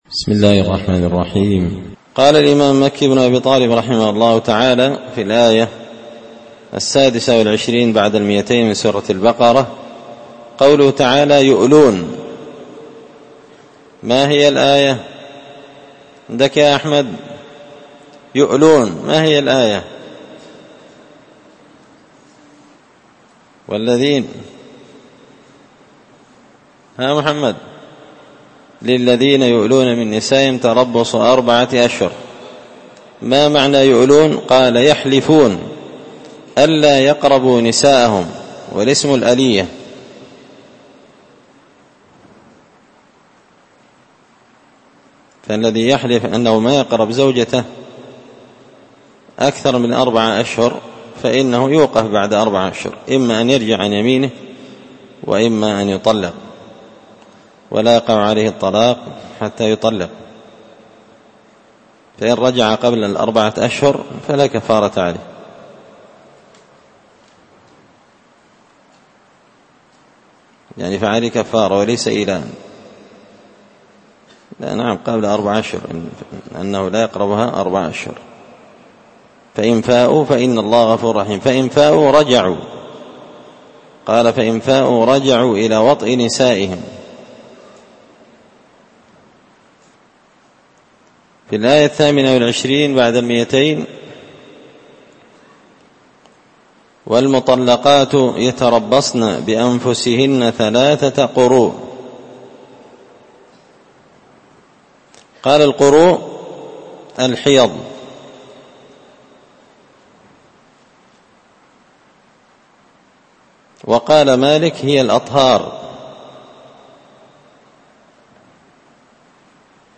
تفسير مشكل غريب القرآن ـ الدرس 42
دار الحديث بمسجد الفرقان ـ قشن ـ المهرة ـ اليمن